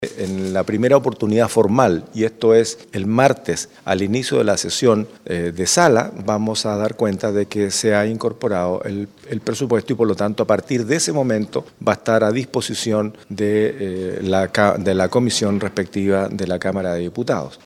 El Presidente de la Corporación, el diputado Iván Flores indicó que durante la jornada de este martes al inicio de sesión de sala se informará de la incorporación oficial del presupuesto para disposición de la comisión respectiva de la cámara baja.